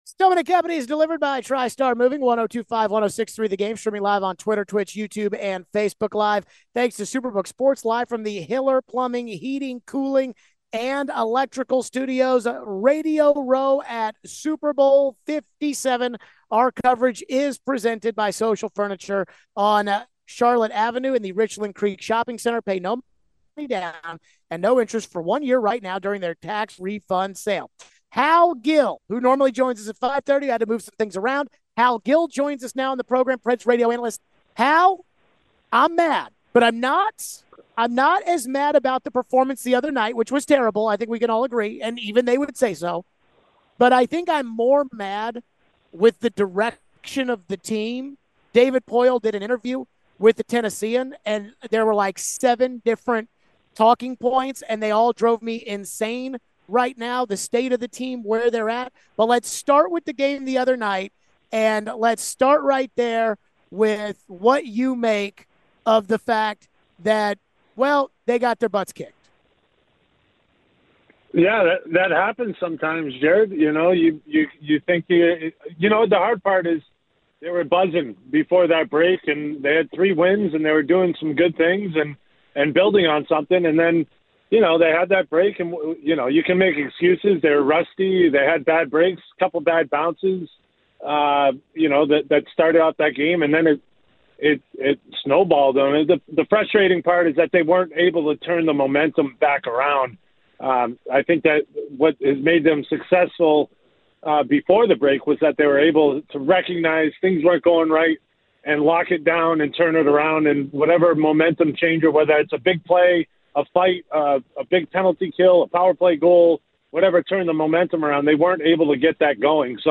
We take your phones. Former Giants WR David Tyree joins us from Radio Row. We wrap up with some more thoughts on the Pacman Jones interview.